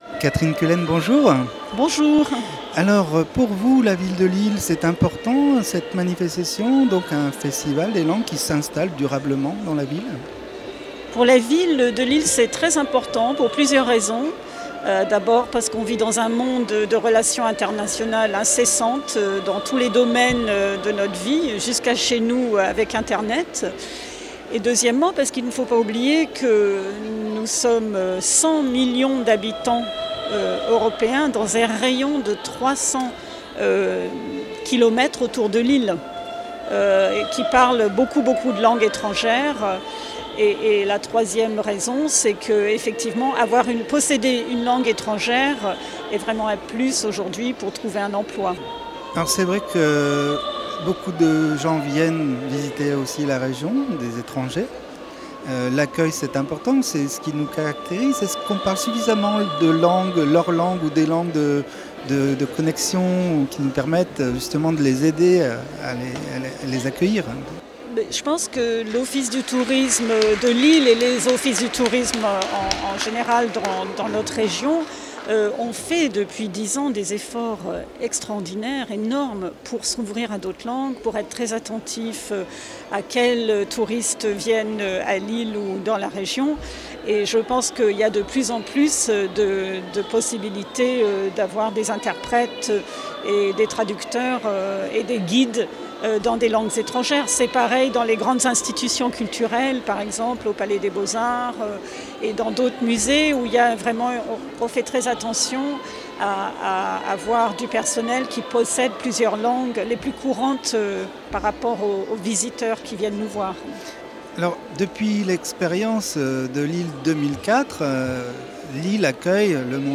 à la CCI de Lille
Interviews réalisées pour Radio Campus
Catherine Cullen, Adjointe à la Culture Mairie de Lille